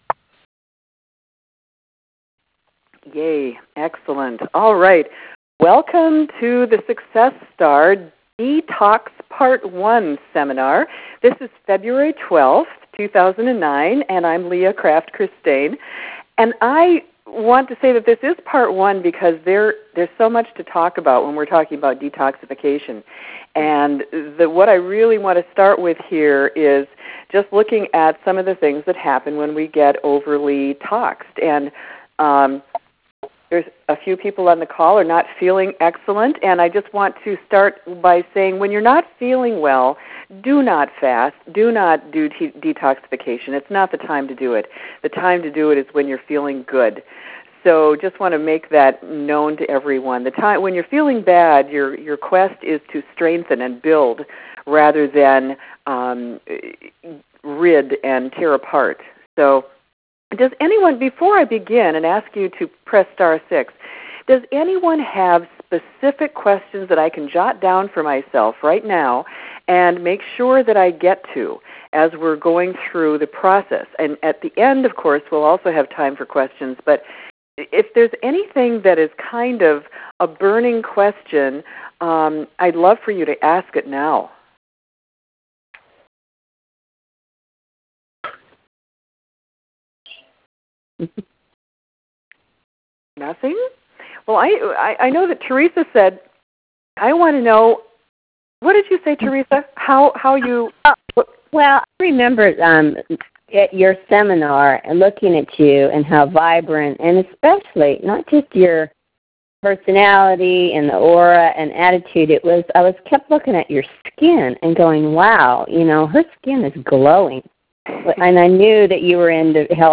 Detox Tele-Seminar Audio & Handout